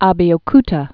A·be·o·ku·ta
bē-ō-ktə)